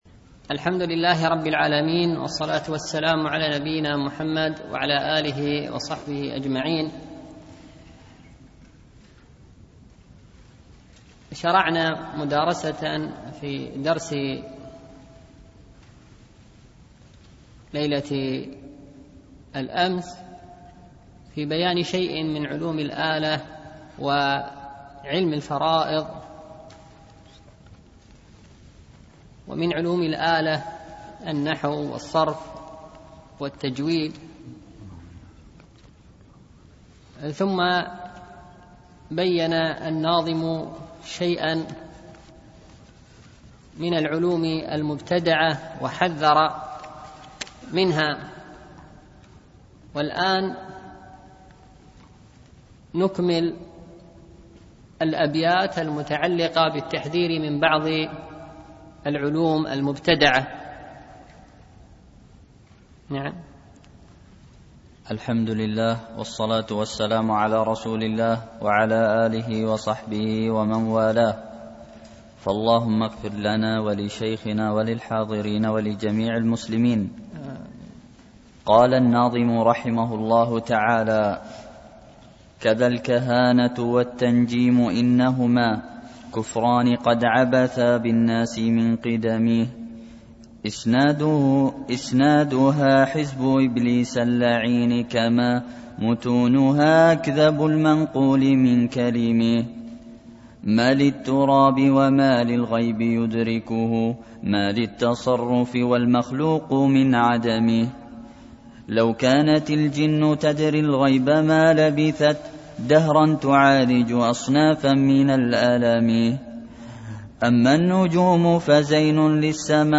شرح المنظومة الميمية في الوصايا والآداب العلمية ـ الدرس العاشر
دروس مسجد عائشة